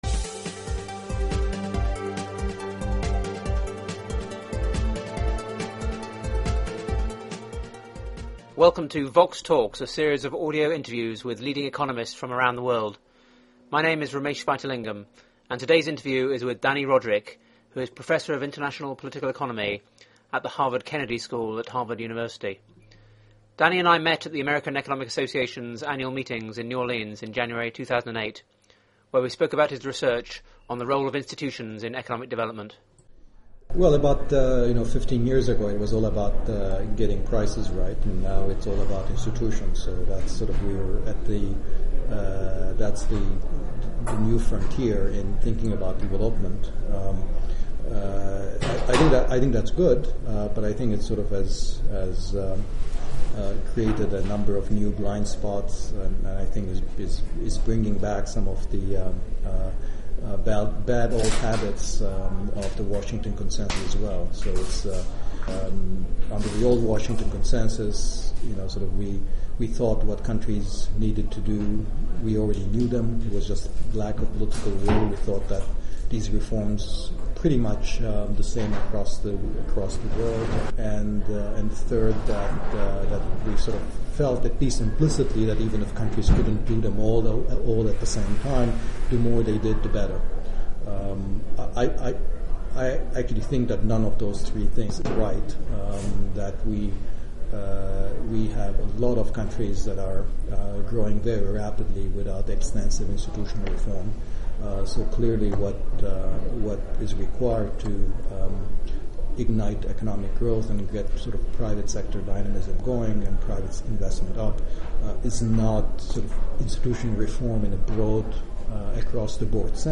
They discuss the use of ‘growth diagnostics’ to identify the binding constraints on economic activity and hence the priorities for policy and institutional reform, drawing on Rodrik’s experiences applying the framework to South Africa. The interview was recorded at the American Economic Association meetings in New Orleans in January 2008.